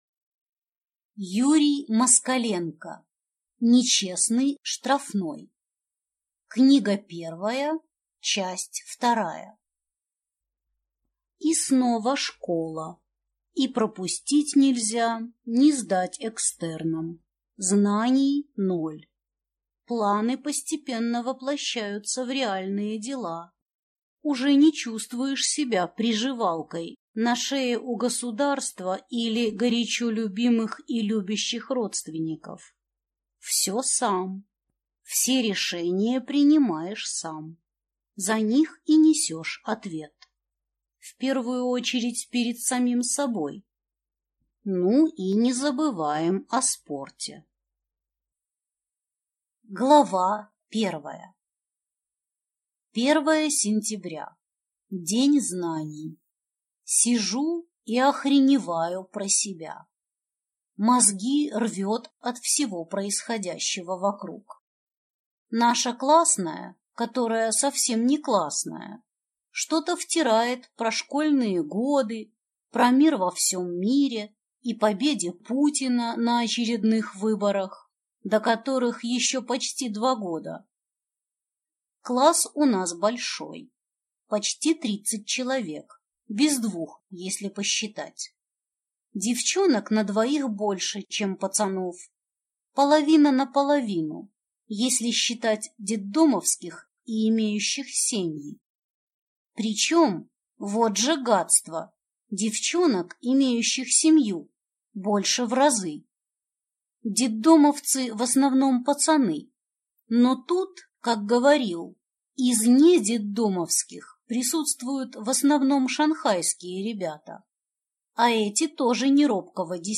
Аудиокнига Нечестный штрафной. Книга первая. Часть вторая | Библиотека аудиокниг